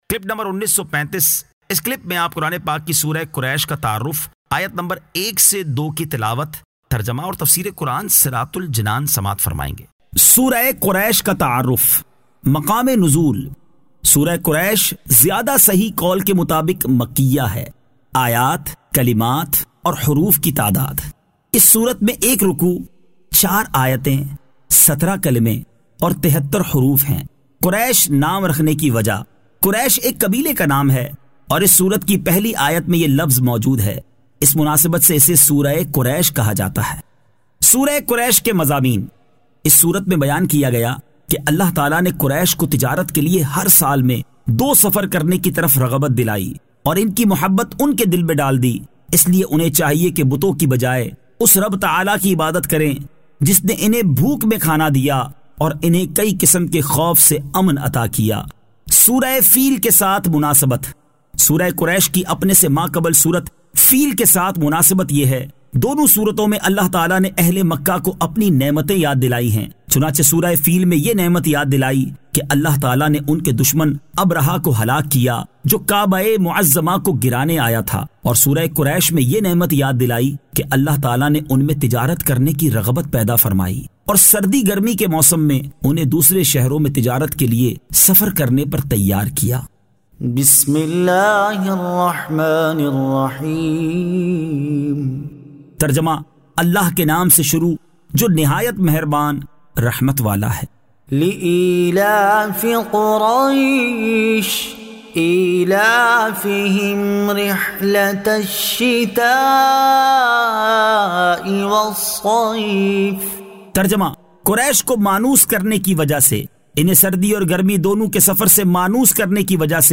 Surah Quraish 01 To 02 Tilawat , Tarjama , Tafseer